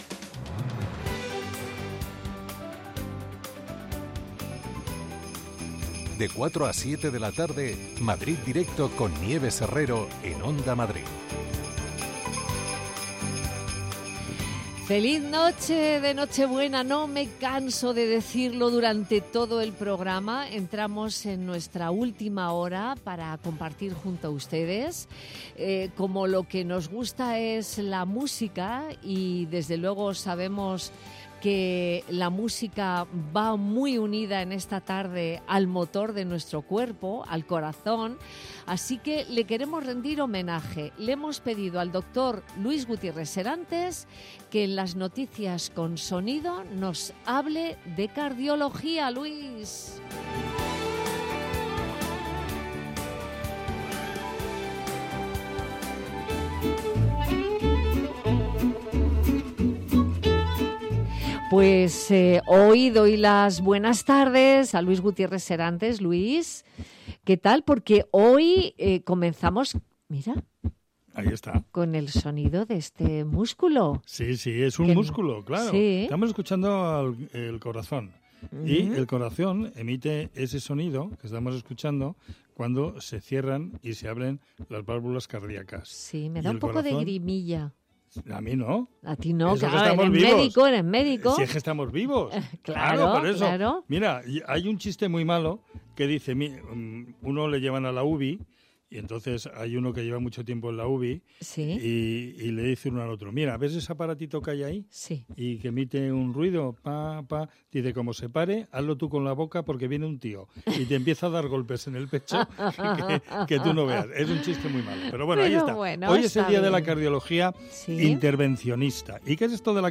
Nieves Herrero se pone al frente de un equipo de periodistas y colaboradores para tomarle el pulso a las tardes. Tres horas de radio donde todo tiene cabida: análisis de la actualidad cultura, ciencia, economía... Te contamos todo lo que puede preocupar a los madrileños.